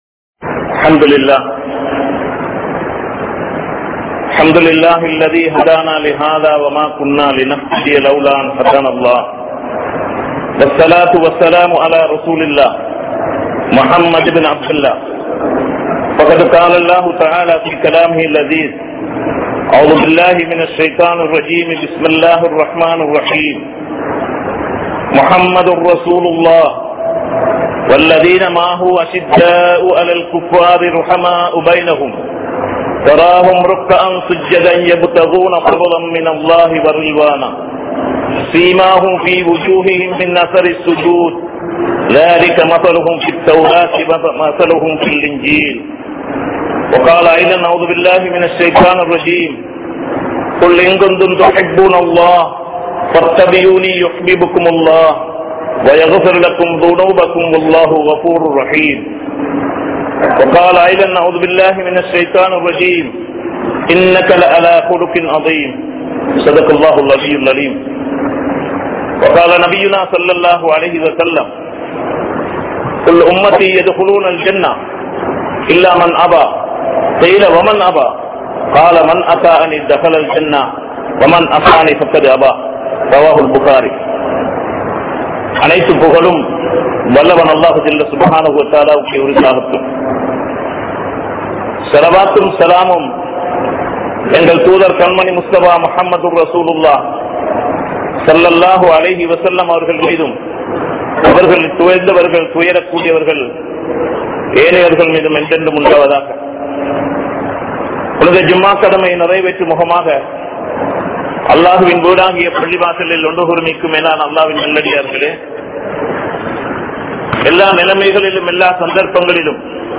Nabi(SAW)Avarhalin Iruthi Naatkal (நபி(ஸல்)அவர்களின் இறுதி நாட்கள்) | Audio Bayans | All Ceylon Muslim Youth Community | Addalaichenai
Kandy,Malay Jumua Masjith